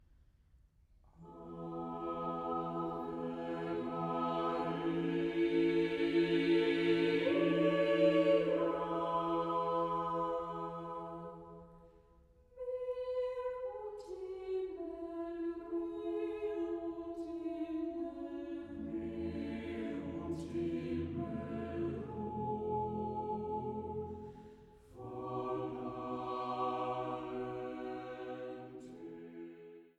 Komposition für gemischten Chor